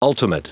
Prononciation du mot ultimate en anglais (fichier audio)
Prononciation du mot : ultimate